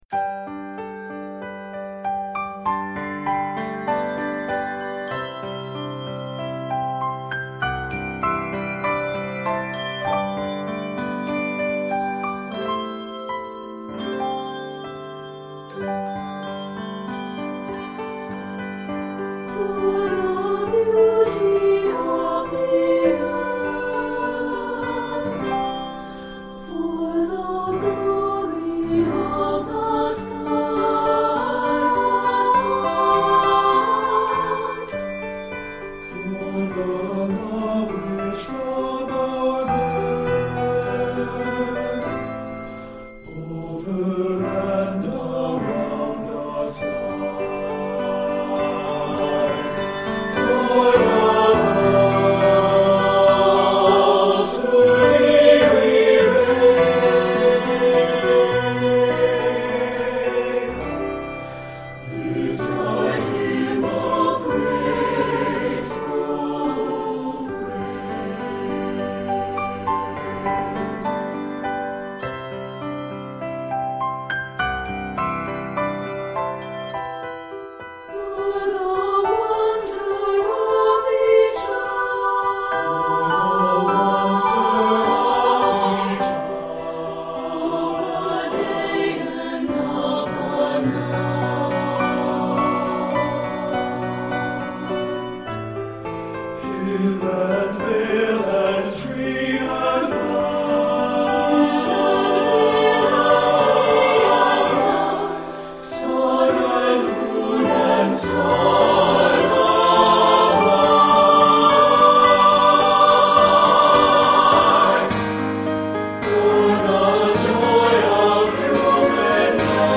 ballad setting
3-5 octaves of handbells
3-5 octave handchimes